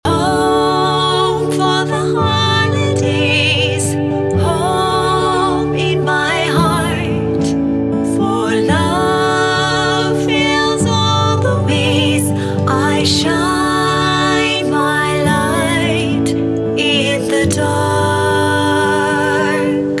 soaring vocals blend in beautiful harmonies